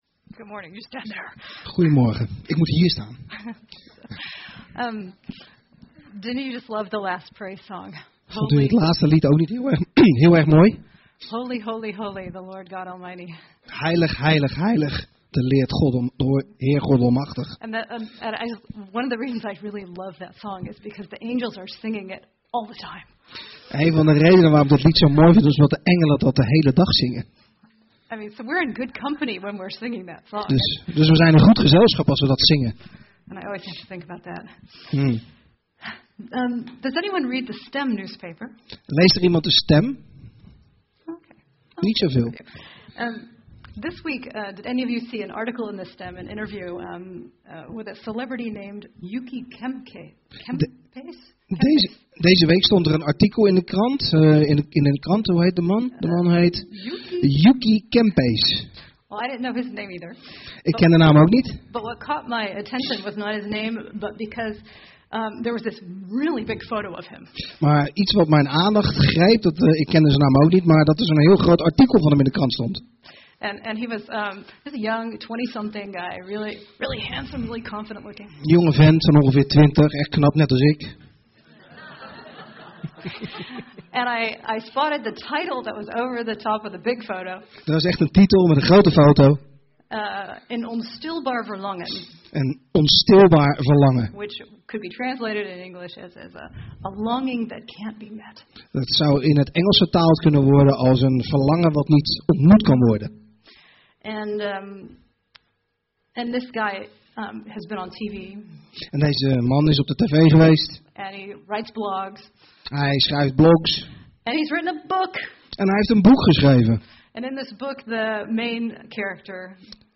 Preken – Pagina 8 – Evangelische Kerk Jefta